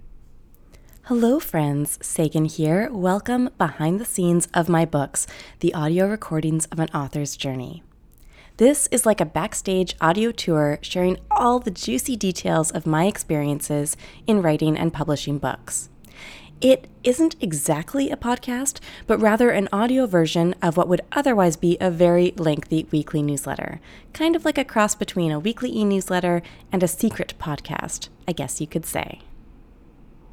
It’s super straightforward in that I record the entire scripted episode in one go, so I don’t have to worry about editing it.